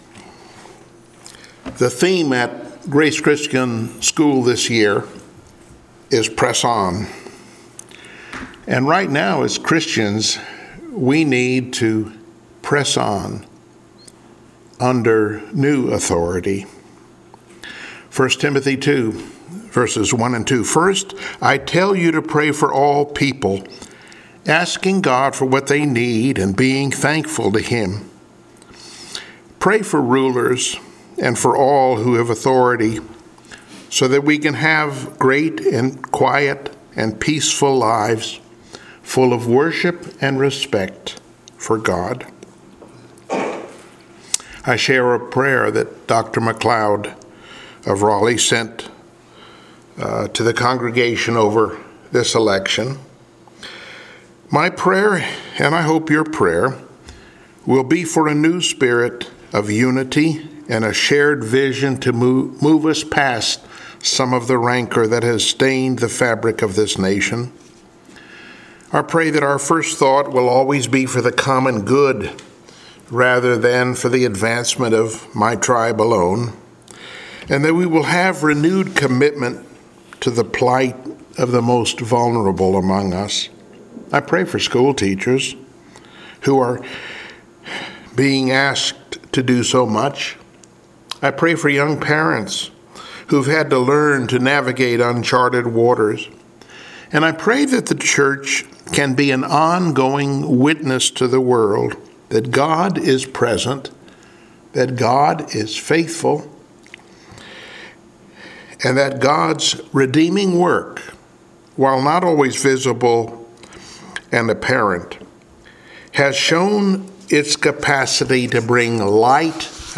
Passage: I Peter 2:13-25 Service Type: Sunday Morning Worship